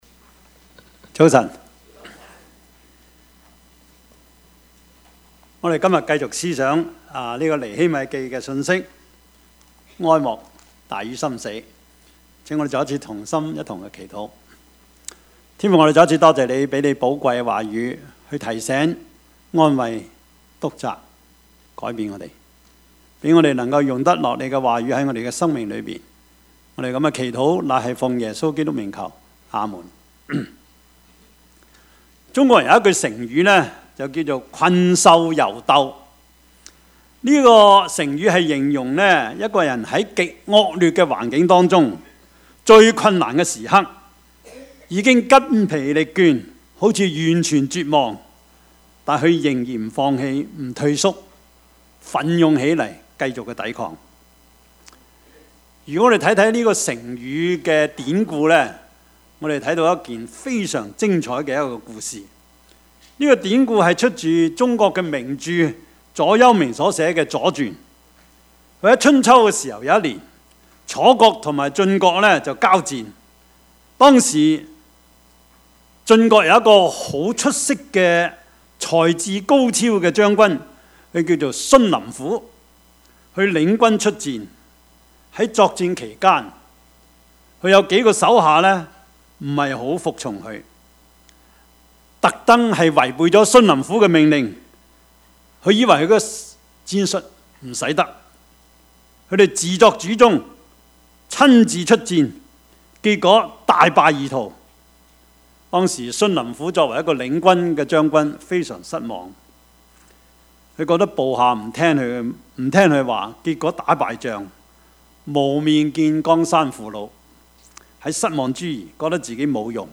Passage: 尼希米記 4:10-23 Service Type: 主日崇拜